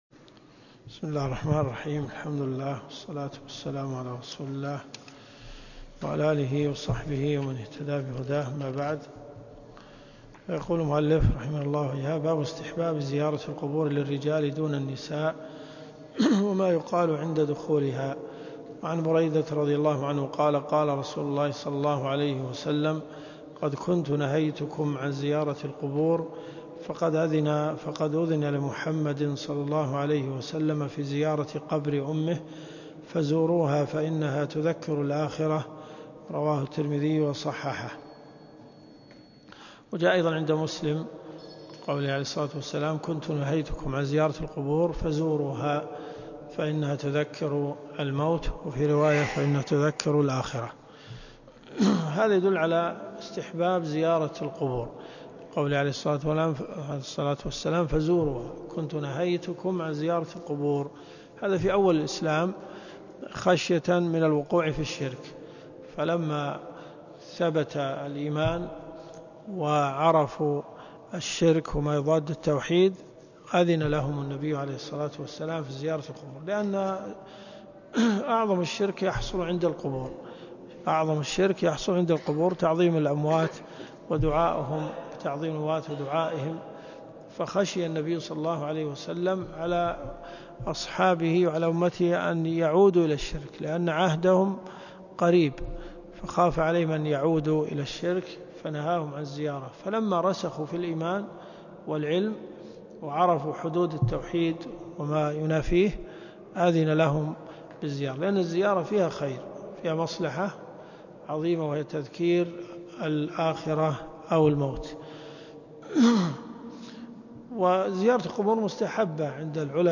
8 - 1433.3 . المنتقى من أخبار المصطفىى . كتاب الجنائز . من حديث 1958 -إلى-حديث 1967 . الرياض . حي أم الحمام . جامع الملك خالد